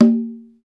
PRC XCONGA0R.wav